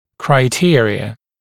[kraɪ’tɪərɪə] [край’тиэриэ] критерии (мн. от criterion)